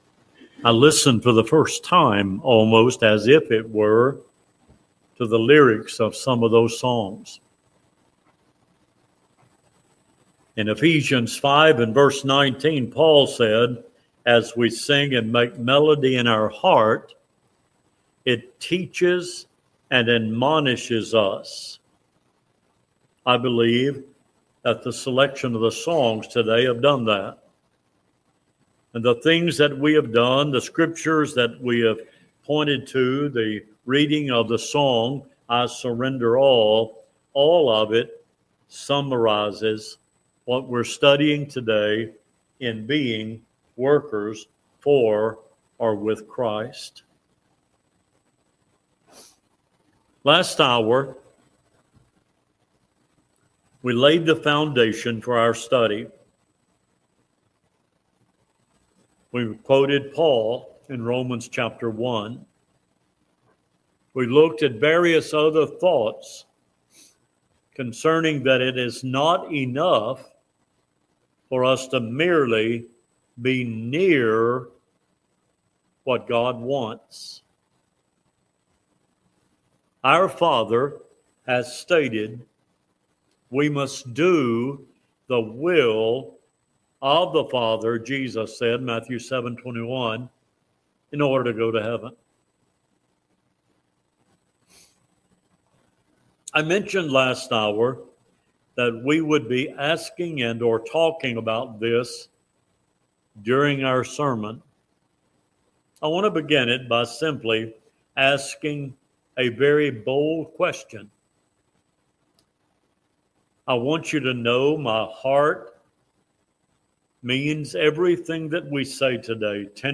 Cedar Grove Church of Christ July 27 2025 AM Sunday Sermon - Cedar Grove Church of Christ